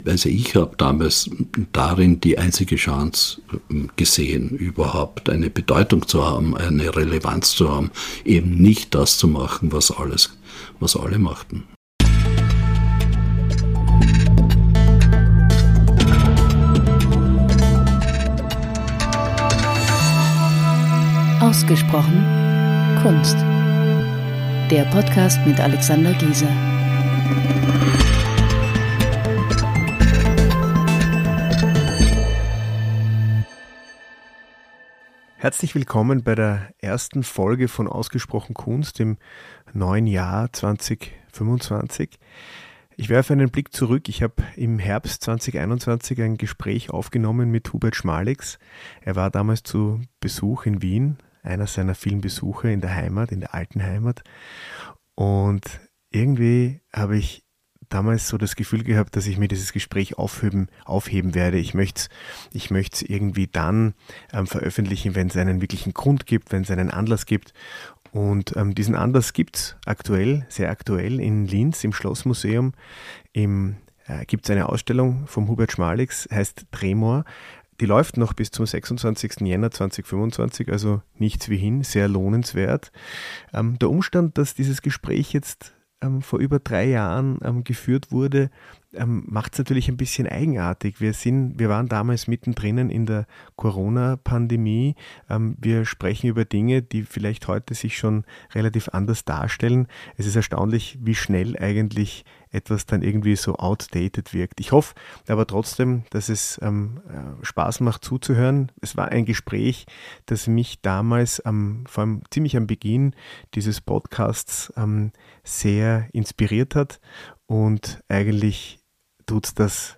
Im Gespräch mit Hubert Schmalix ~ Ausgesprochen Kunst Podcast
Das Gespräch haben wir im Frühjahr 2021 aufgenommen.